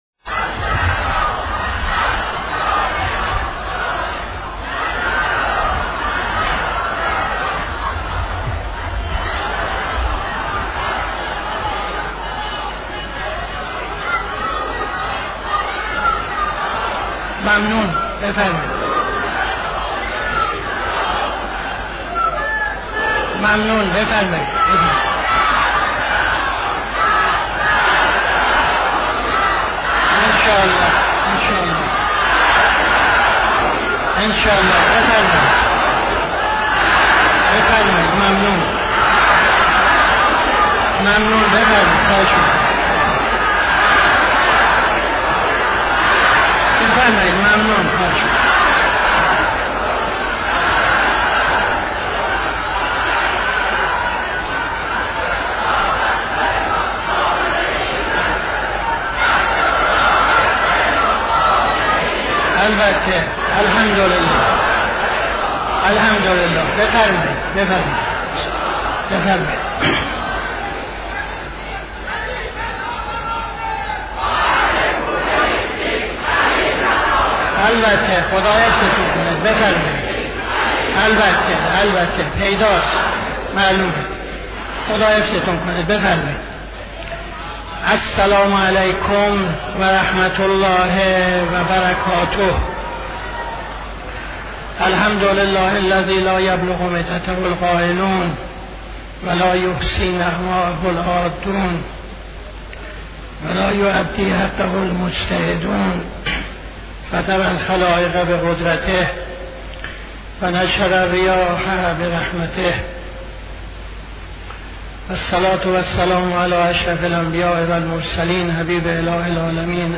خطبه اول نماز جمعه 01-12-76